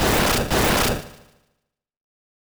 failed-round.wav